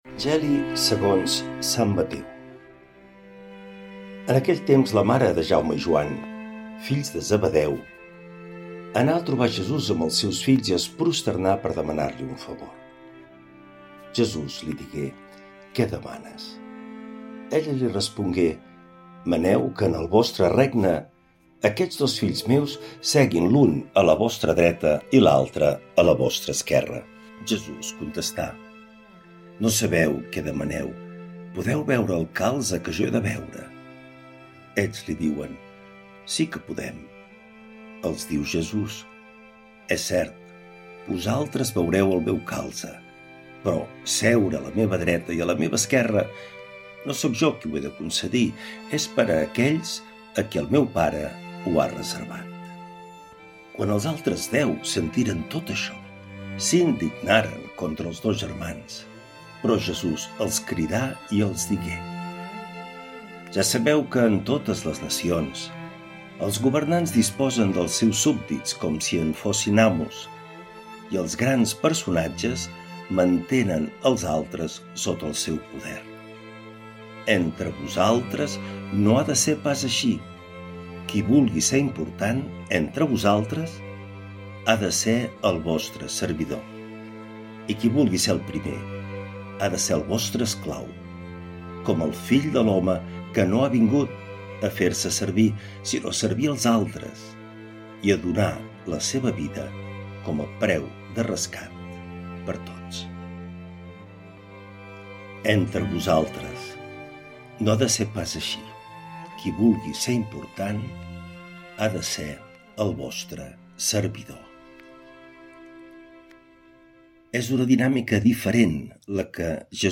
L’Evangeli i el comentari de divendres 25 de juliol del 2025.
Lectura de l’evangeli segons sant Mateu